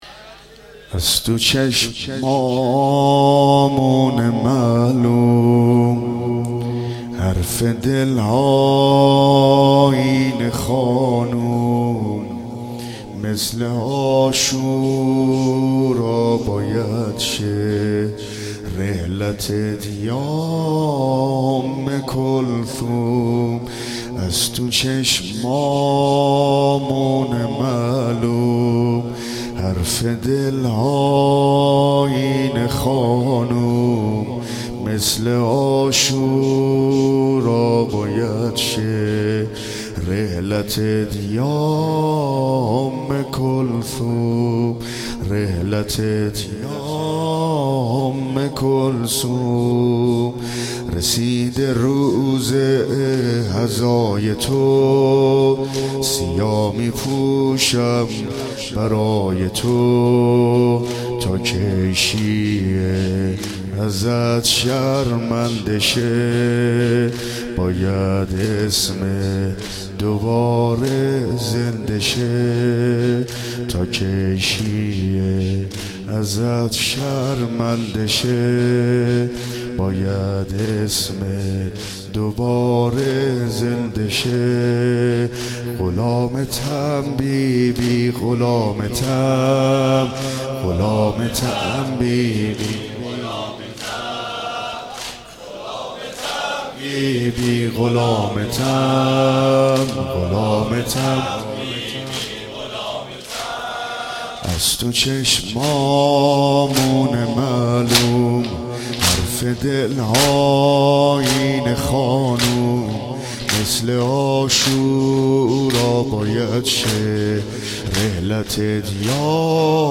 با مداحی : حاج عبدالرضا هلالی برگزار شد
روضه
سینه زنی زمینه ، شور